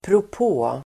Ladda ner uttalet
Uttal: [prop'å:]